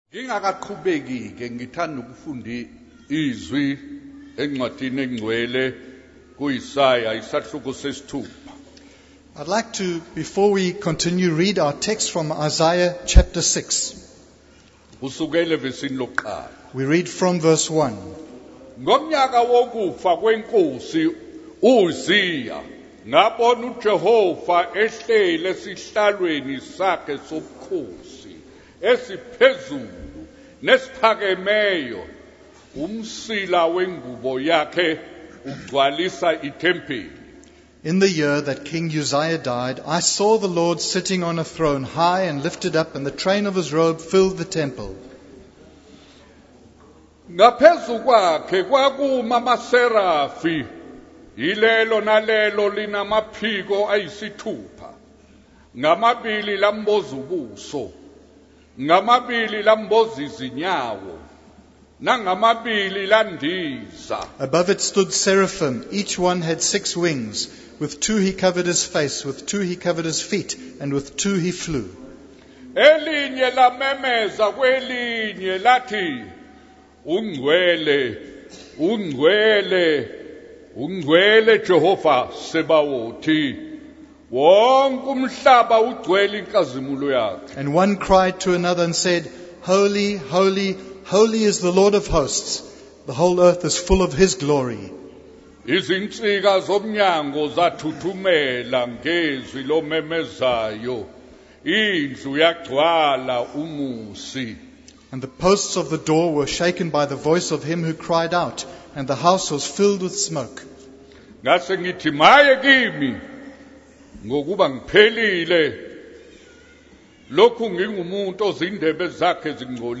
In this sermon, the preacher begins by reading from Isaiah chapter 6, where Isaiah sees the Lord sitting on a throne in the temple. The preacher emphasizes the holiness of God and the separation between those who live for the Lord and those who do not. The sermon then transitions to a wedding ceremony, where the preacher encourages the couple to keep their promise faithfully and for their marriage to be a testimony of God's work in their lives.